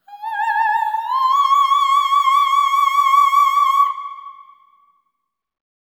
OPERATIC15-L.wav